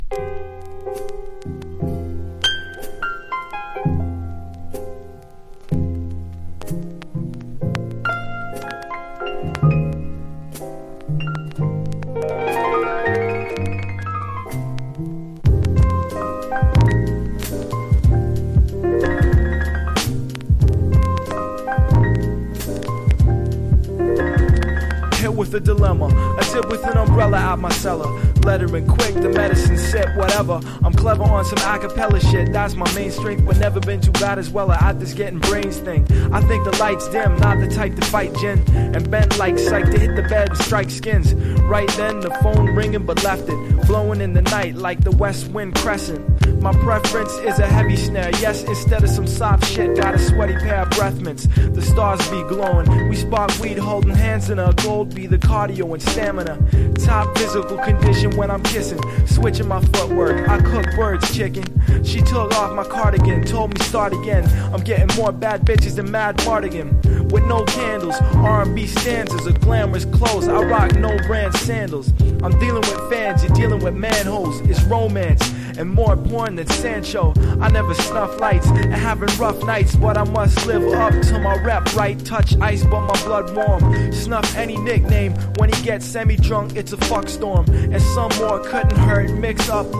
# JAZZY HIPHOP